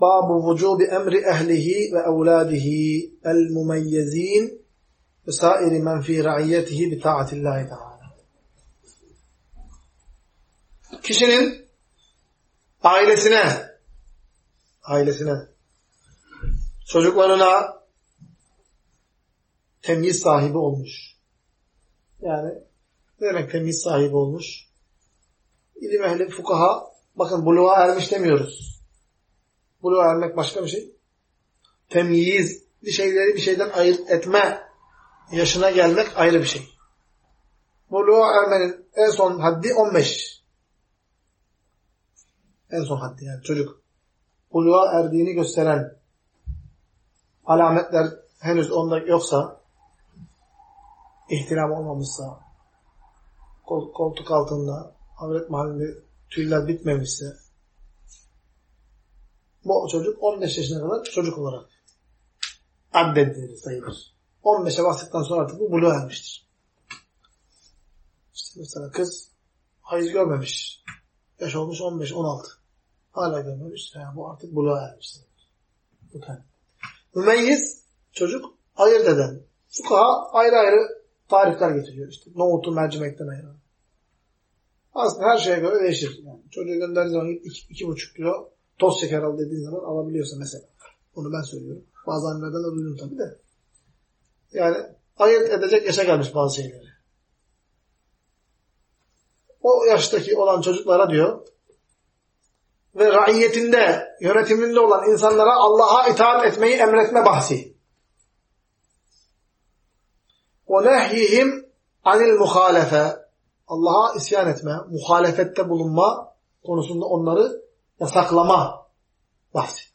Ders - 38.